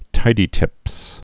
(tīdē-tĭps)